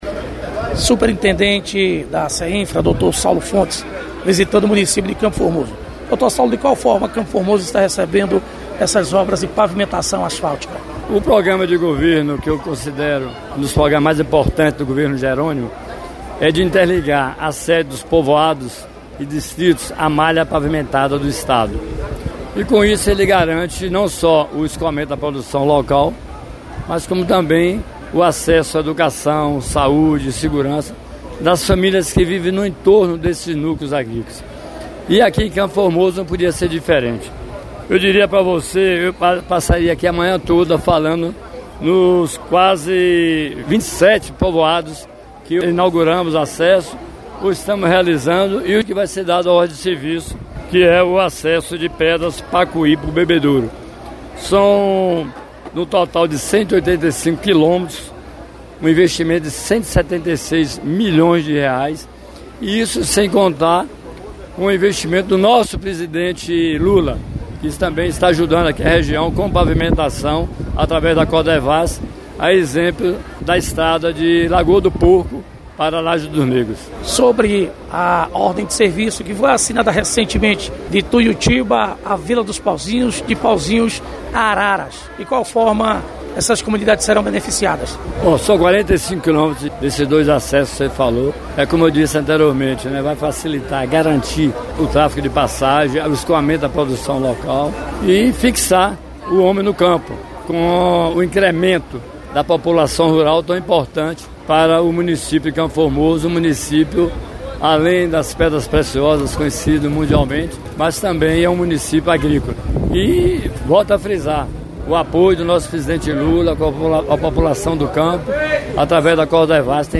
Superintendente da Seinfra-BA, Dr. Saulo Fontes, visitando CFormoso, comenta sobre as obras de pavimentação asfáltica recebidas do governo para o município.